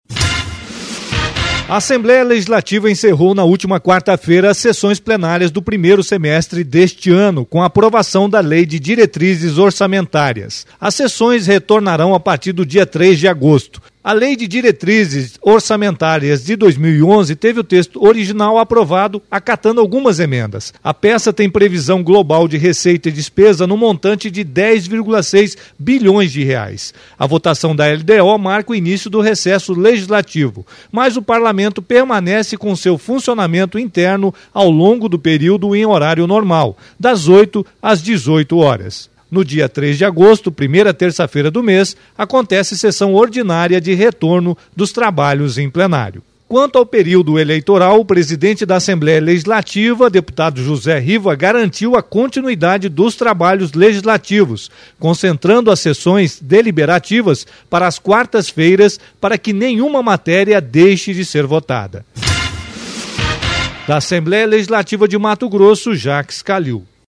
Apresentação do repórter